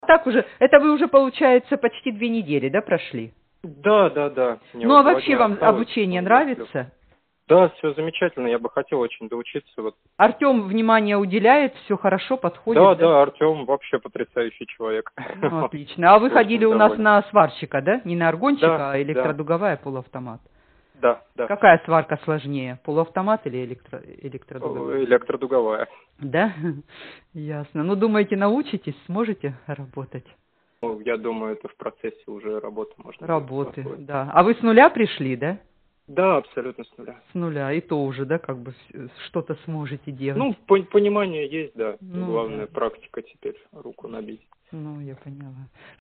Аудио Отзывы